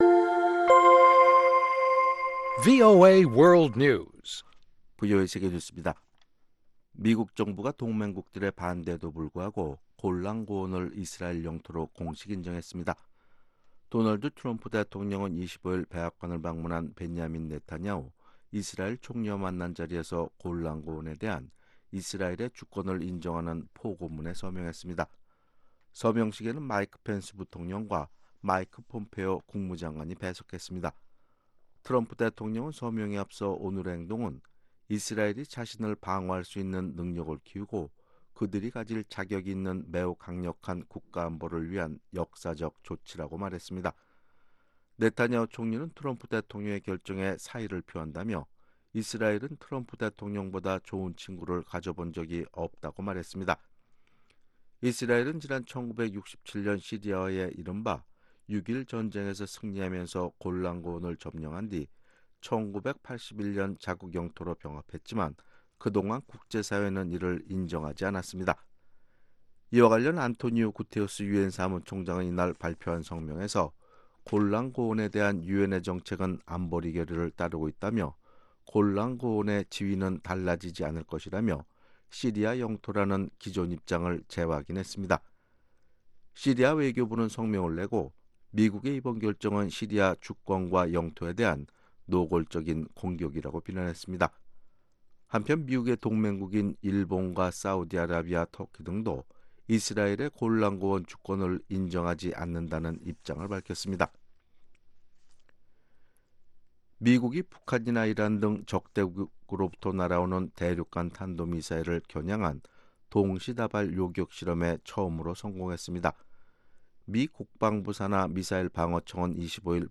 VOA 한국어 아침 뉴스 프로그램 '워싱턴 뉴스 광장' 2019년 3월 27일 방송입니다. 백악관은 트럼프 대통령이 철회를 언급한 제재는 기존의 제재가 아니라 새롭게 발표될 제재였다고 밝혔습니다. 미국 정부는 북한의 해상 거래를 겨냥한 주의보를 갱신하면서, 북한이 불법으로 유류를 수입하는 수법을 공개했습니다.